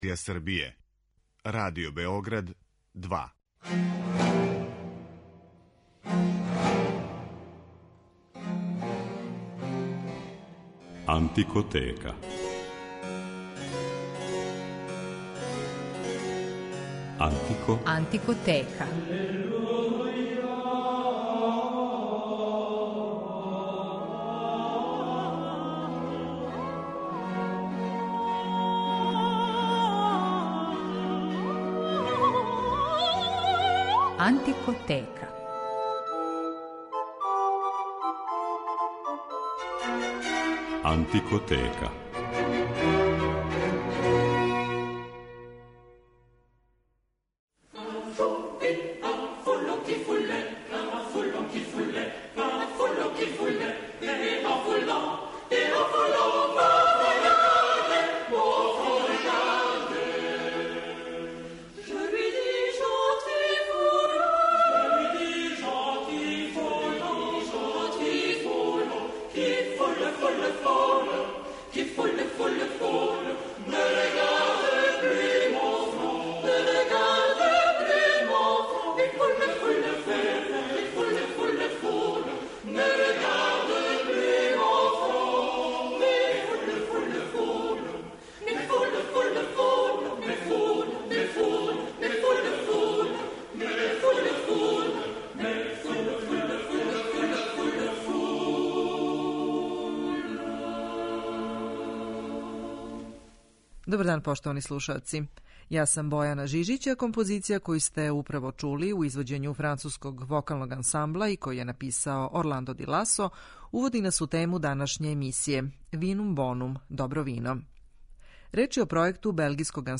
Винске песме 16. века